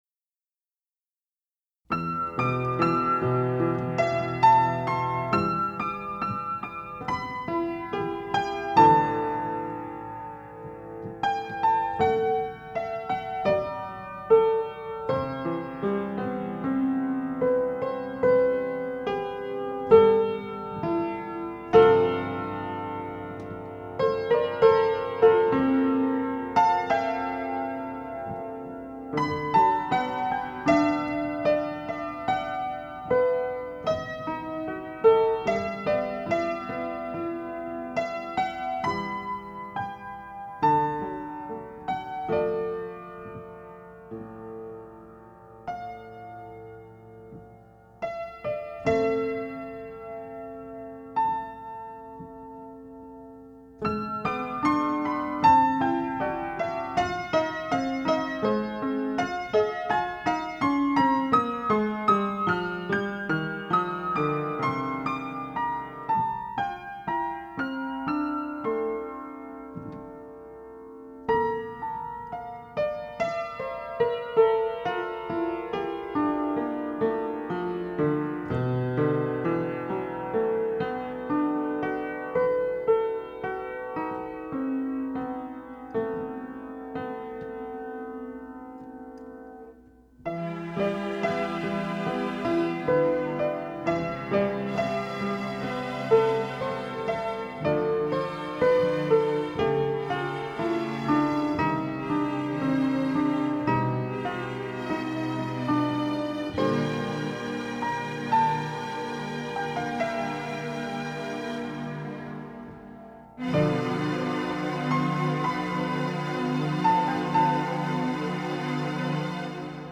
Rules Do Not Apply, (Old, slightly corrupted file)
(First half is simple and melancholy, then heats up to a heavy metal/classical experience to end in bang-crash dissonance at the end.
piano
guitars, synth, and drums, using overdubbing on a Tascam unit to get it all